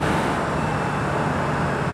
highway / oldcar / dec3.ogg